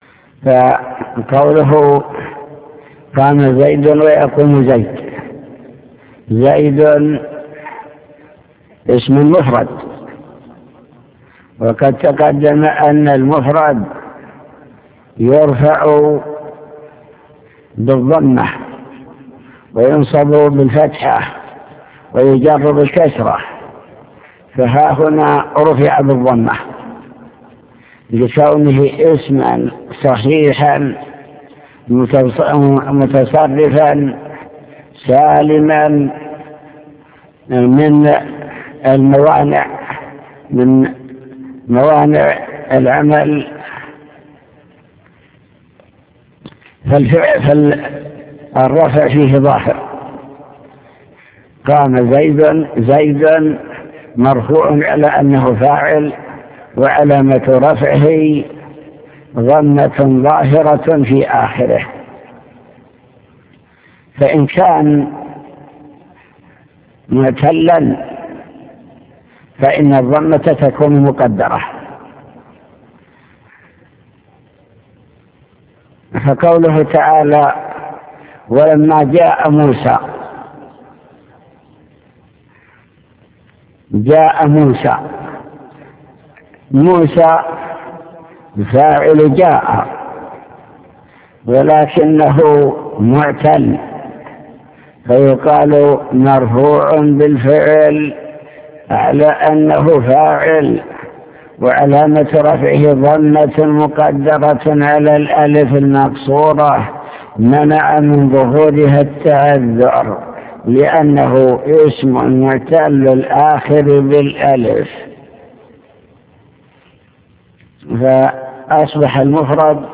المكتبة الصوتية  تسجيلات - كتب  شرح كتاب الآجرومية باب الفاعل أقسام الفاعل أنواع الفاعل الظاهر